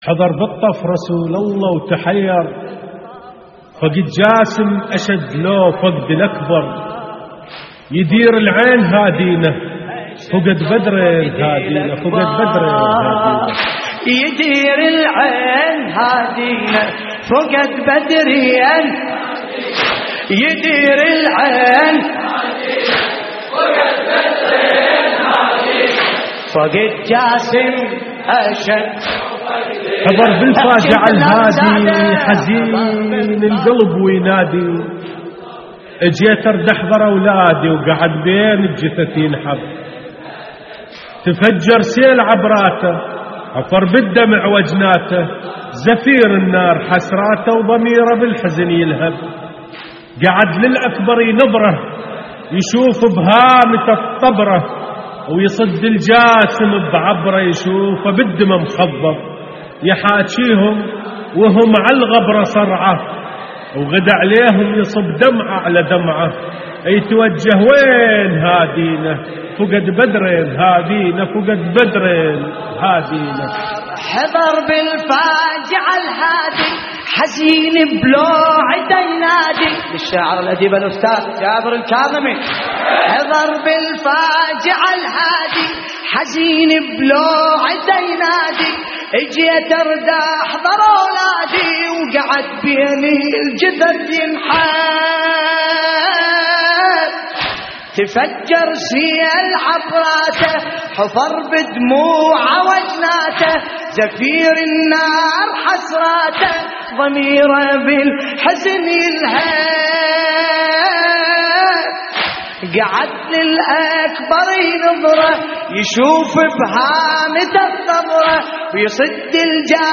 تحميل : حضر بالطف رسول الله وتحير فقد جاسم أشد لو فقد الأكبر / الرادود باسم الكربلائي / اللطميات الحسينية / موقع يا حسين